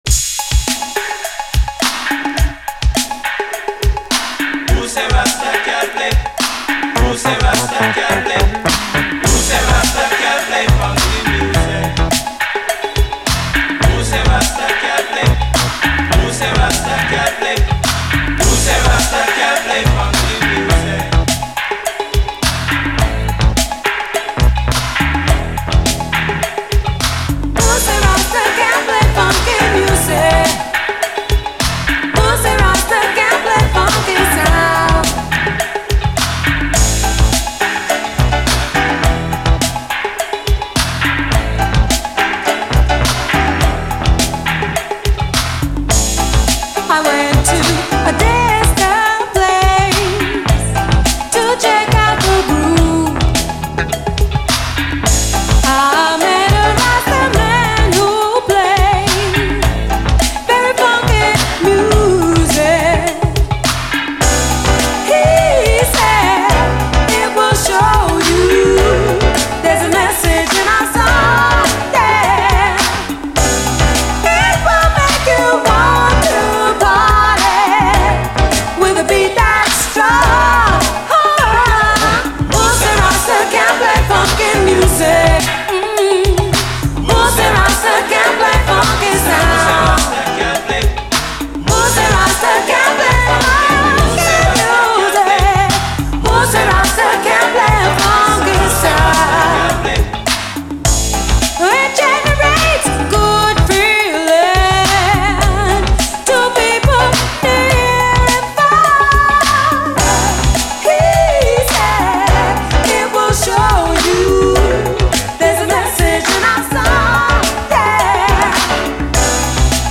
SOUL, 70's～ SOUL, REGGAE, HIPHOP
ファンキーな曲でありつつ、キュンと来るメロディアスで美しい展開が非常に素晴らしいです。後半にはラップ入り。